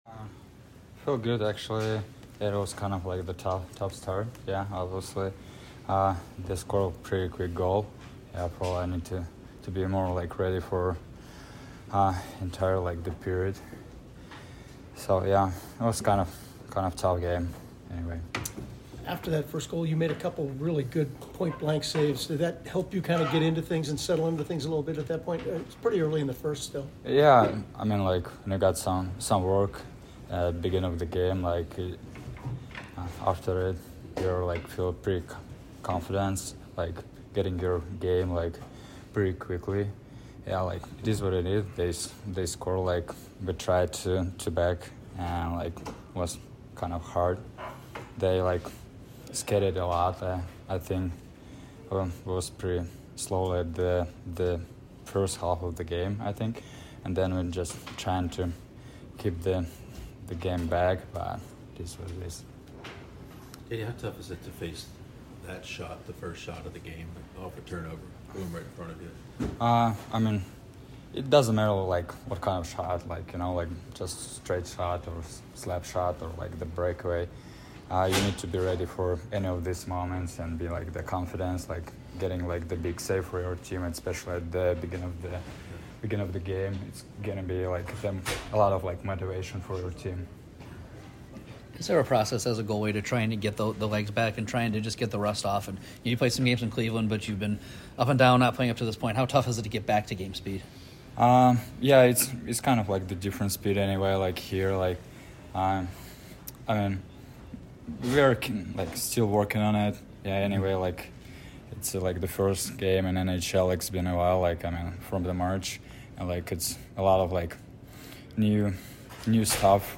BLUE JACKETS POST-GAME AUDIO INTERVIEWS